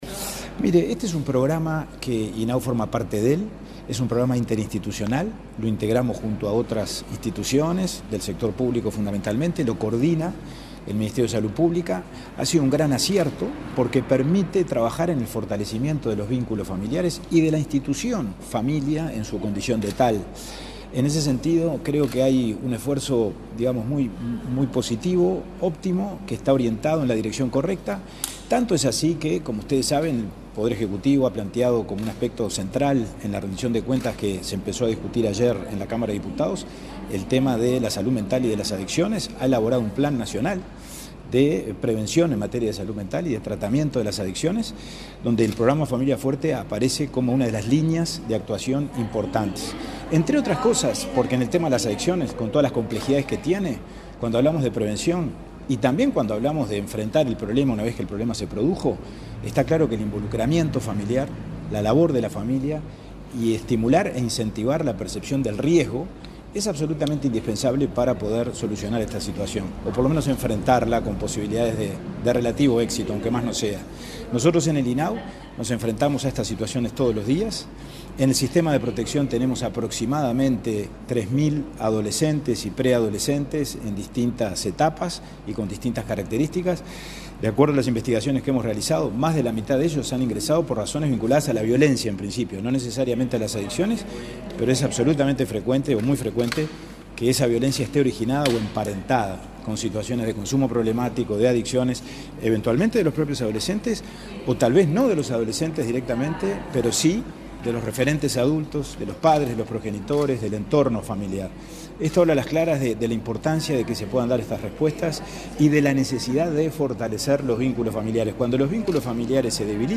Declaraciones del presidente de INAU, Pablo Abdala
Declaraciones del presidente de INAU, Pablo Abdala 11/07/2023 Compartir Facebook X Copiar enlace WhatsApp LinkedIn El presidente del Instituto del Niño y el Adolescente del Uruguay (INAU), Pablo Abdala, participó este martes 11 en Montevideo en el lanzamiento del programa Familias Fuertes, una iniciativa de prevención para reforzar los lazos familiares y promover una comunicación efectiva entre padres e hijos.